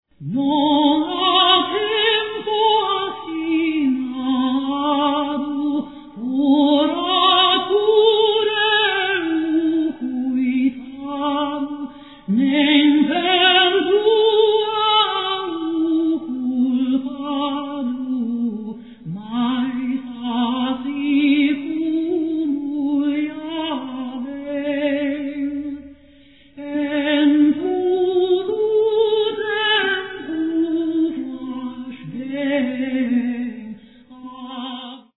countertenor
lute